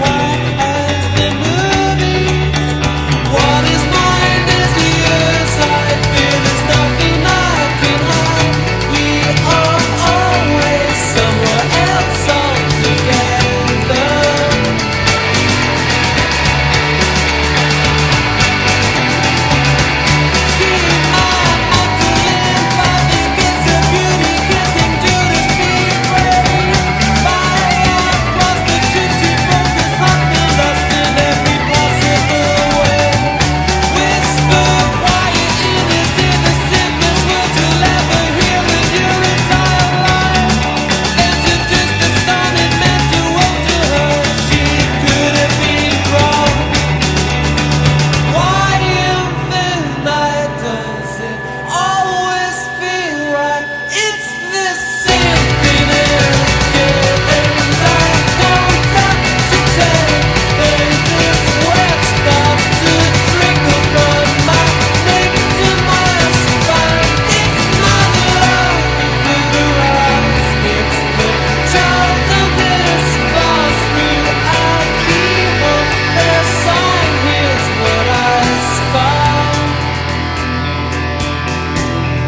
即戦力なキラー・ロックンロール/オールディーズ！